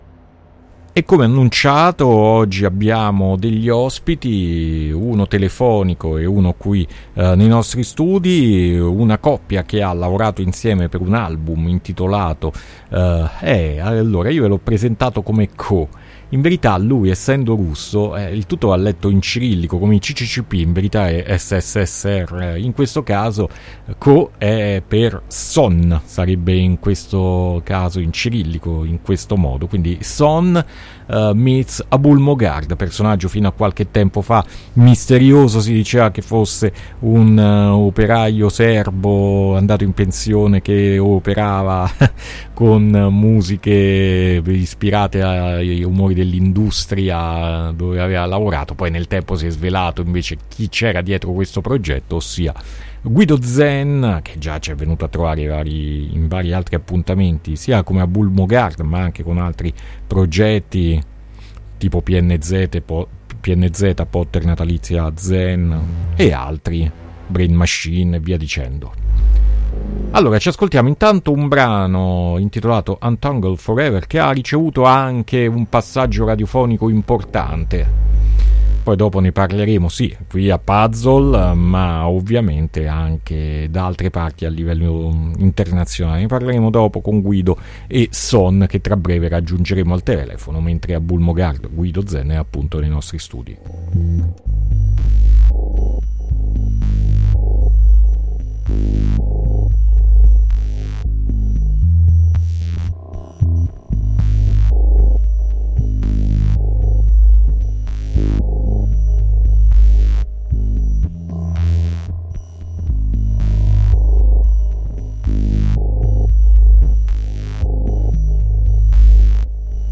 INTERVISTA
uno in studio e l’altro raggiunto telefonicamente.